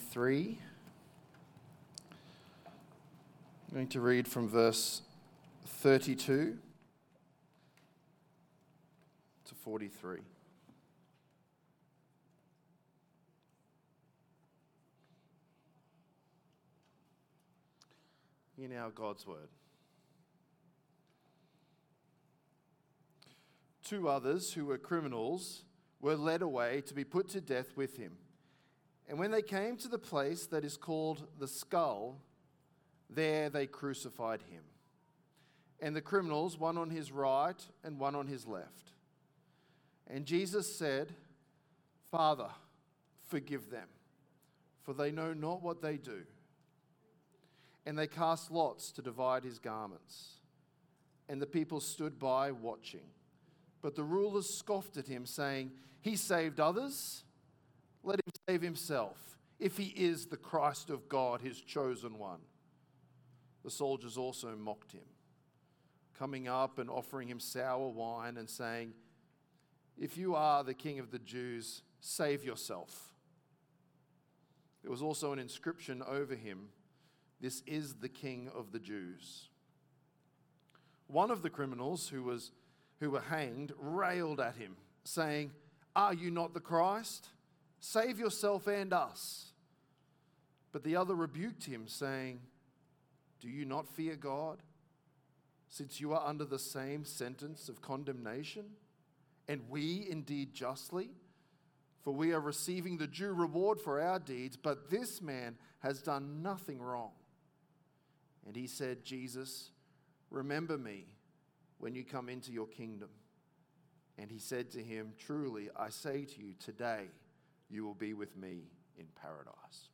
Current Sermon
Good Friday